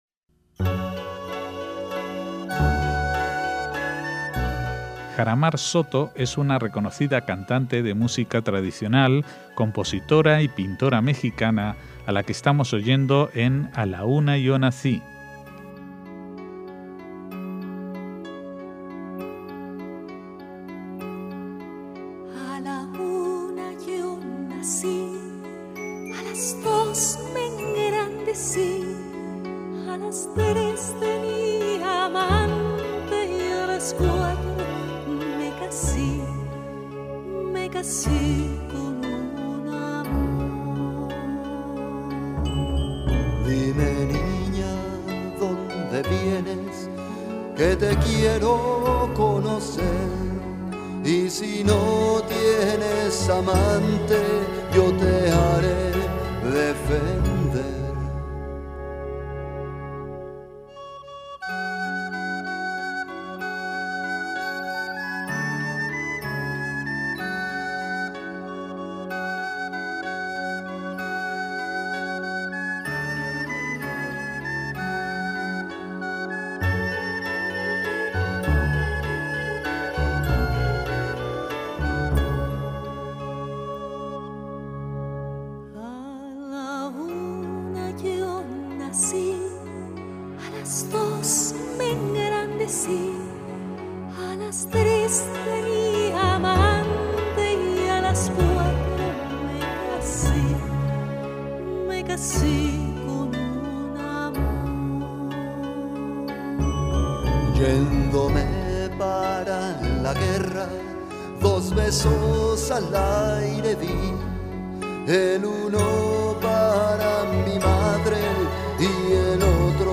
MÚSICA SEFARDÍ - Jaramar Soto es una cantante, compositora y pintora mexicana, nacida en 1954., con una amplia trayectoria en grupos de música antigua e incluso de jazz.